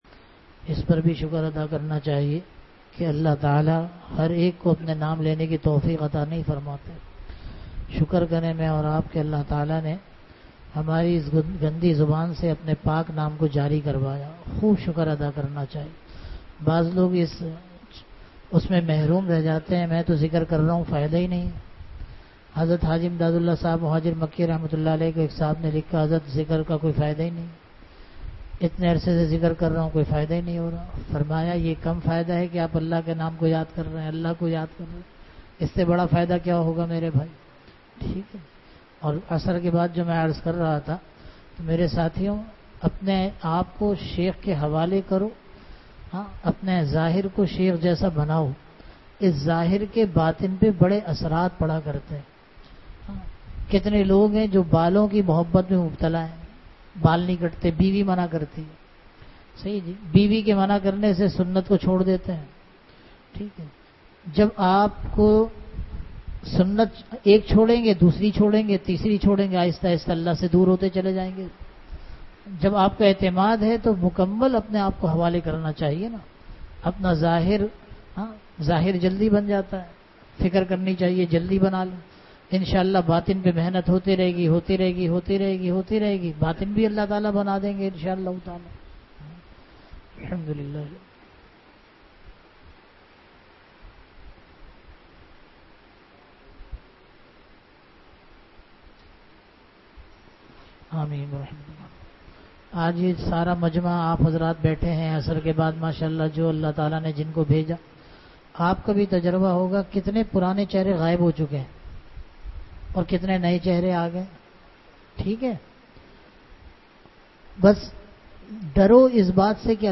Bayanat